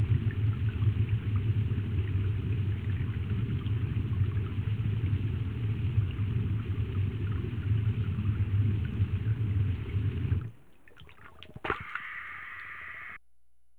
Index of /90_sSampleCDs/E-MU Producer Series Vol. 3 – Hollywood Sound Effects/Water/UnderwaterDiving
UNDERWATE00L.wav